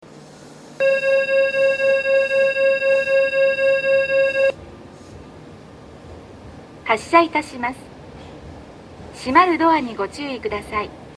B線ホームで収録すると、真上にA線の線路があるのでよくガタンゴトンと被ります・・。
到着放送